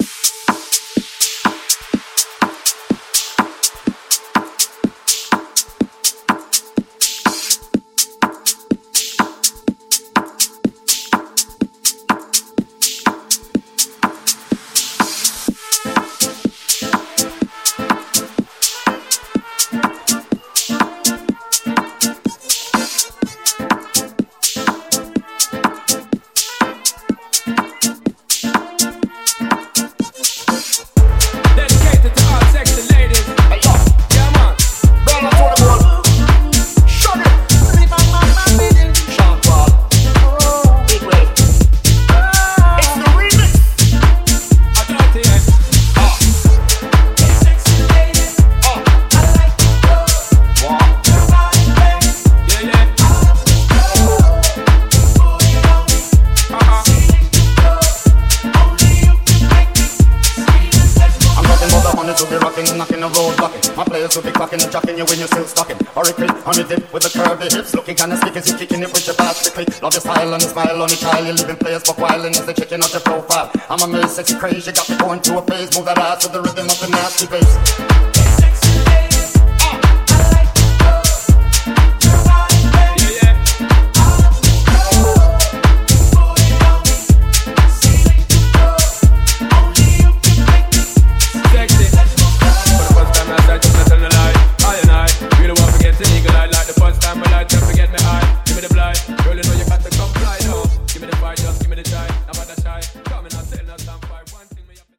Genre: DANCE
Clean BPM: 124 Time